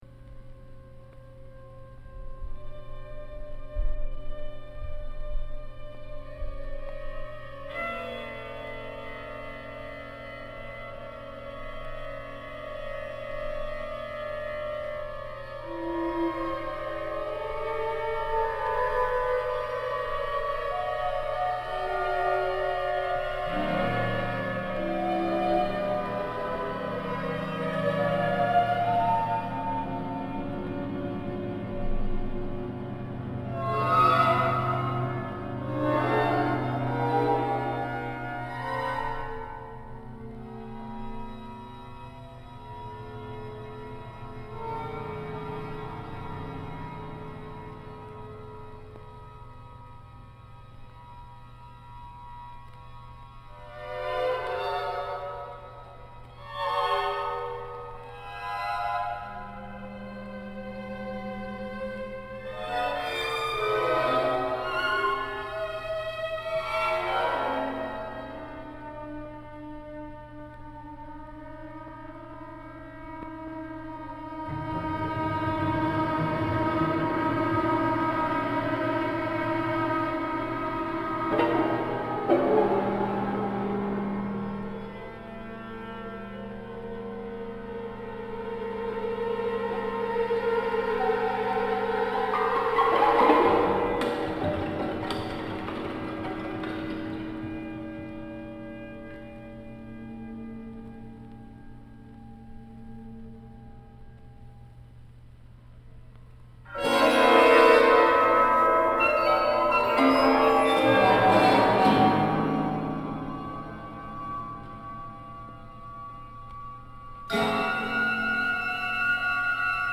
recovered from an old vinyl record.
for orchestra Back